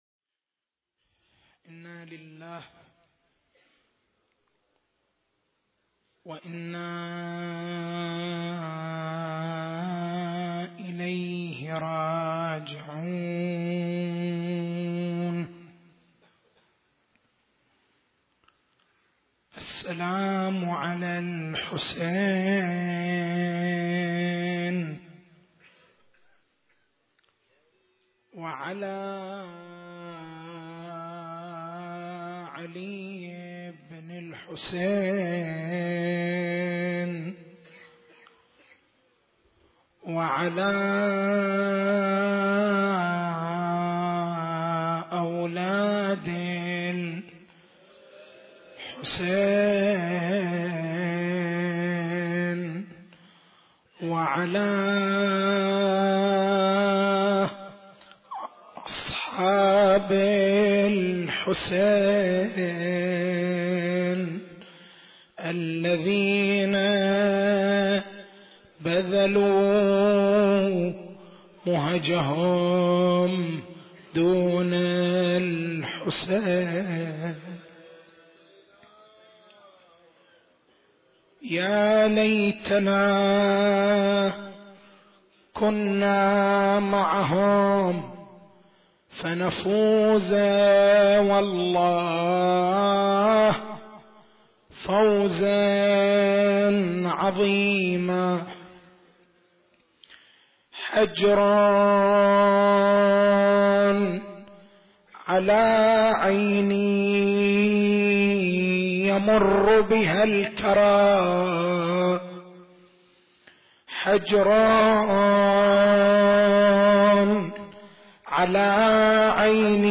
نص المحاضرة